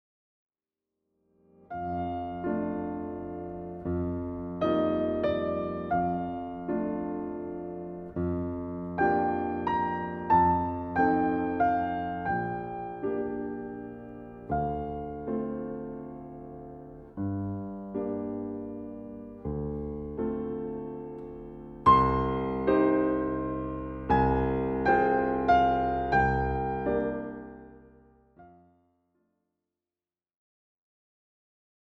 Instrumental (236)
Les pièces les plus méditatives du répertoire pour piano
Des musiques qui favorisent calme et sérénité.
Format :MP3 256Kbps Stéréo